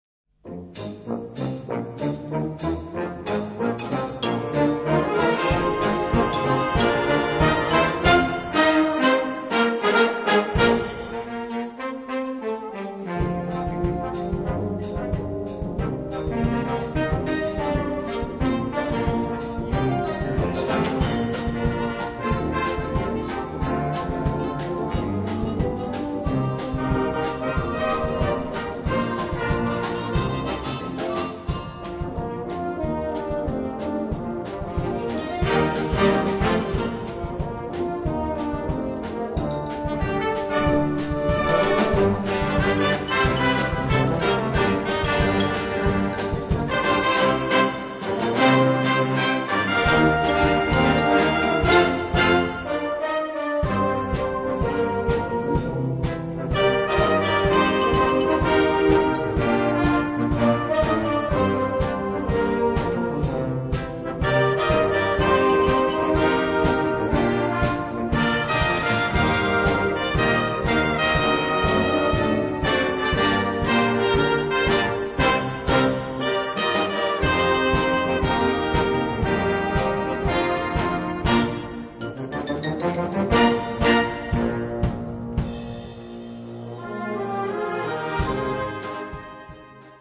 Partitions pour orchestre d'harmonie, ou fanfare.
Concert Band